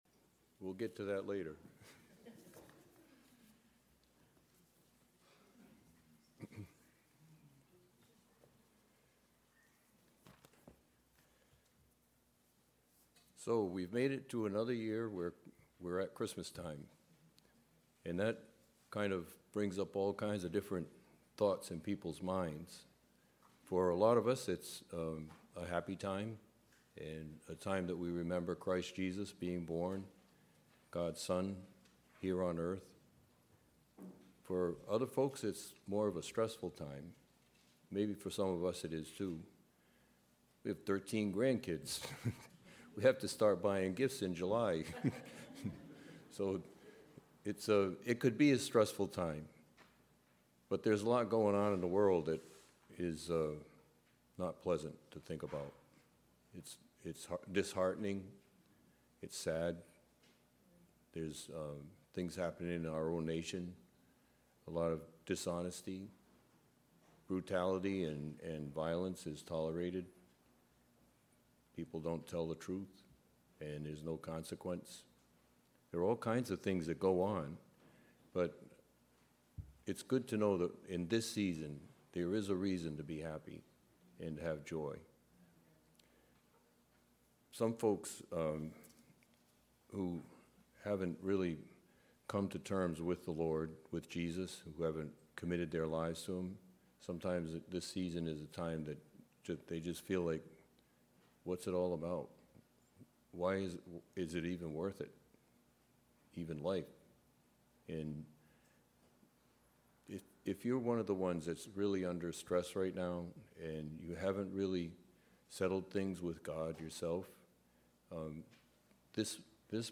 Listen to sermons by our pastor on various topics.
Guest Speaker